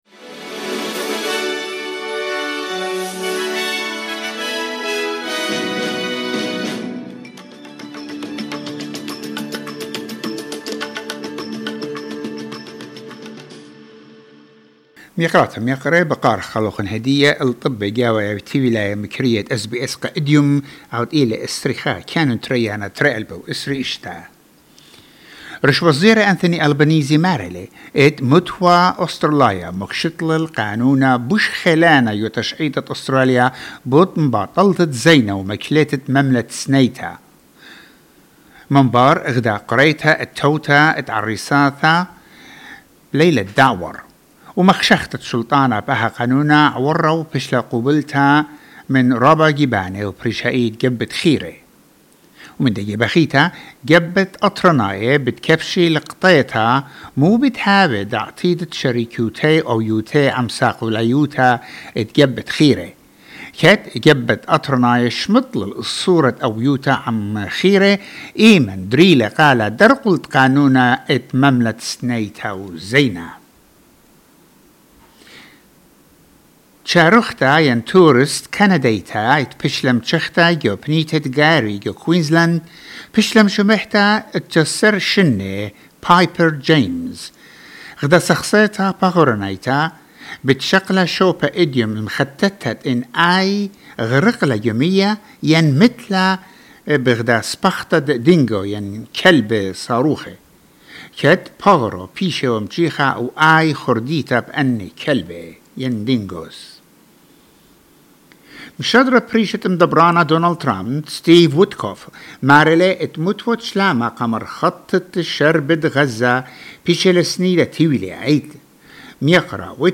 Newsflash 21 January 2026